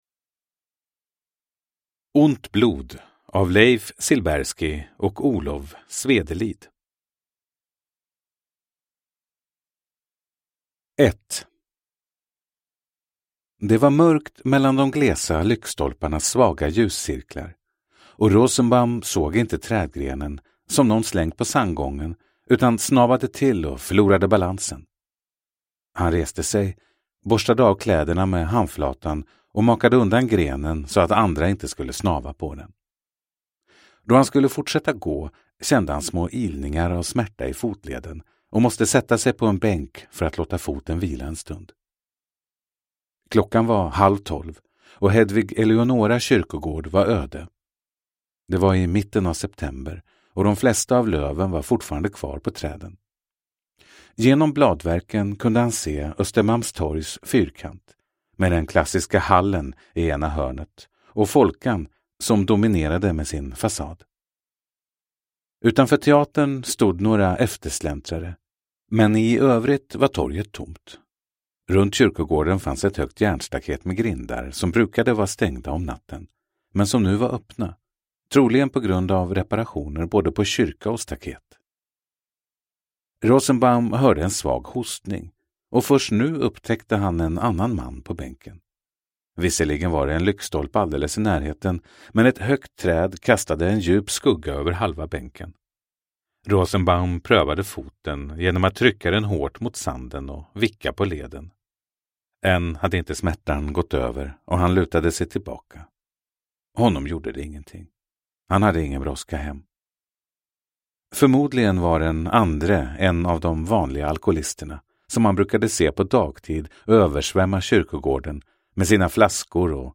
Ont blod – Ljudbok
Deckare & spänning Njut av en bra bok